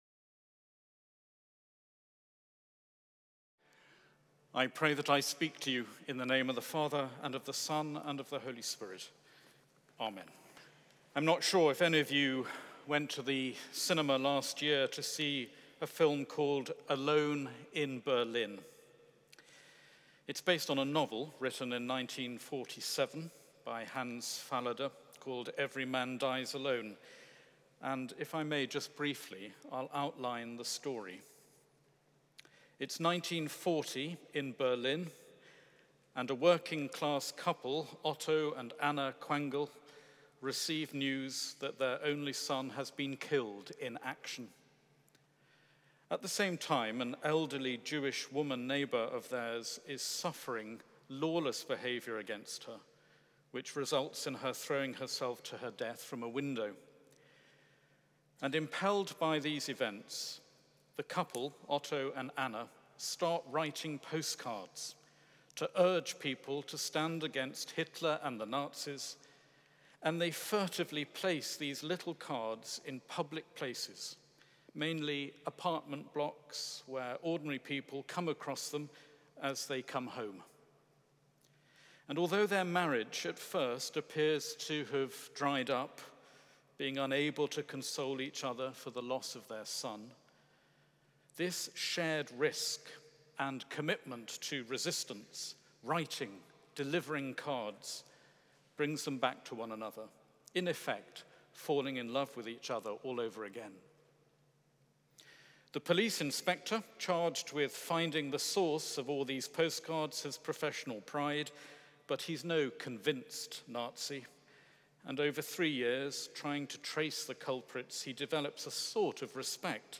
Sunday Eucharist Sermons MT18 | St John's College, University of Cambridge